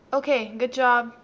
good job neutral,